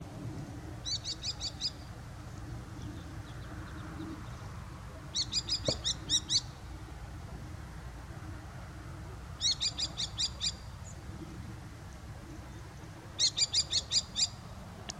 Lagarteiro común
Canto